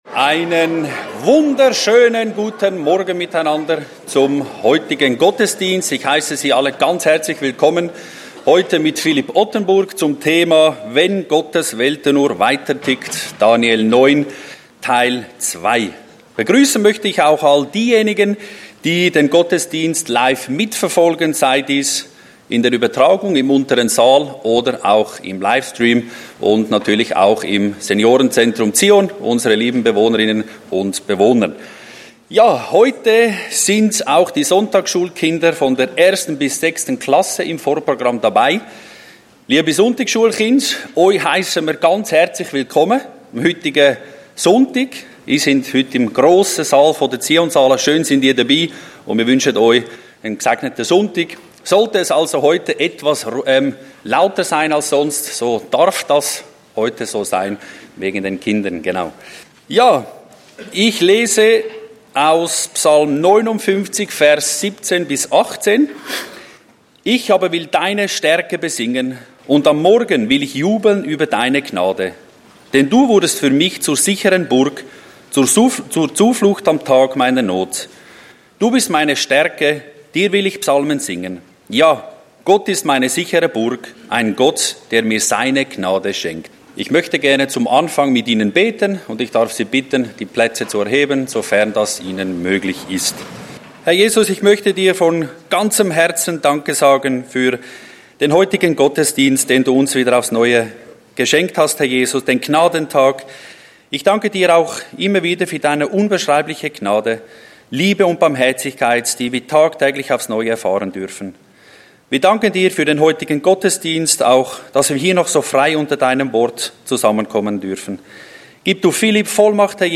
Einleitungen Gottesdienst